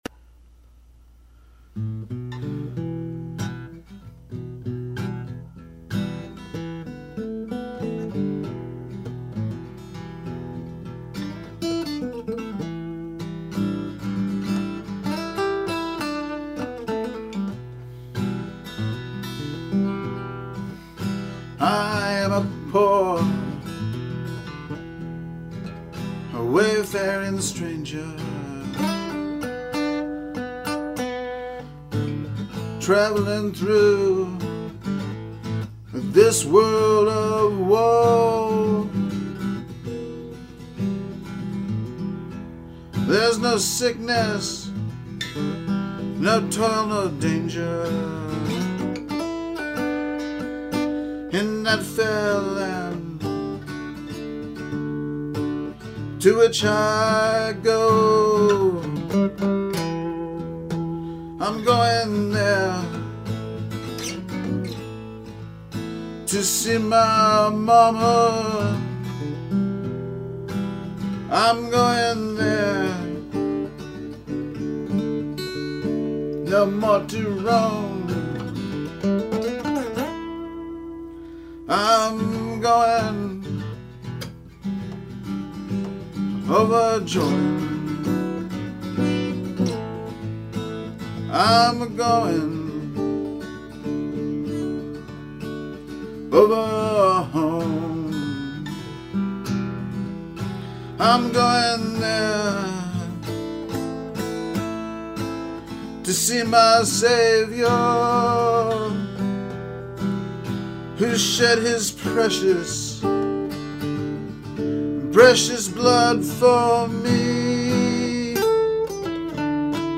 "Wayfaring Stranger" A traditional Southern spiritual.
The others were recorded at Purple Productions, using only a large diaphragm vocal mic about four feet away from where I sat with a guitar. I used an Ovation Elite, and the old spiritual is just the guitar and my appropriately flat, nasal Appalachian voice.